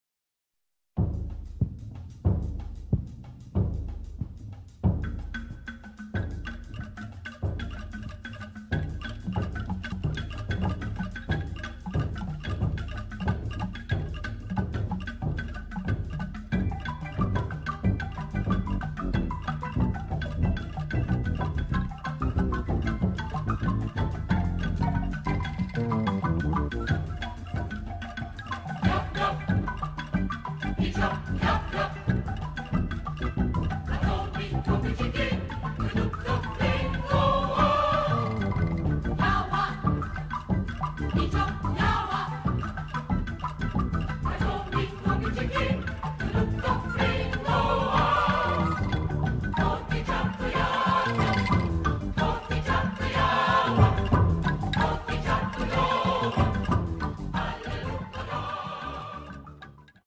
Film score